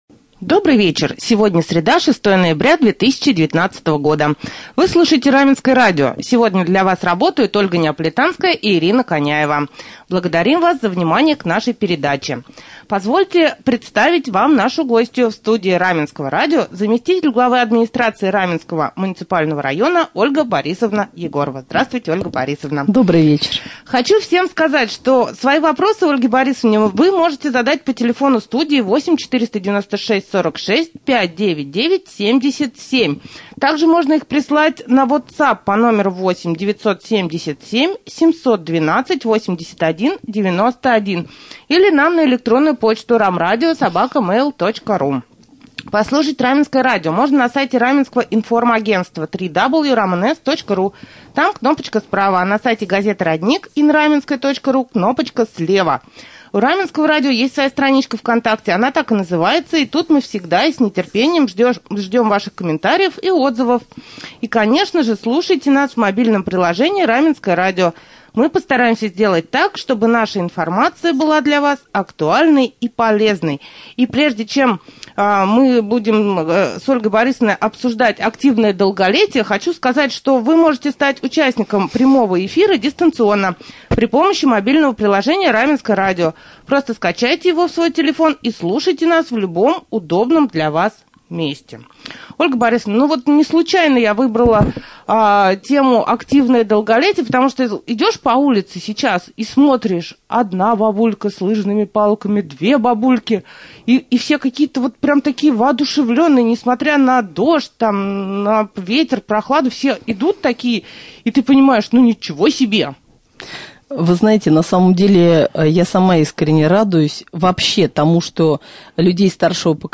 Об этом и не только в прямом эфире Раменского радио рассказала заместитель главы Раменского г.о. Ольга Егорова.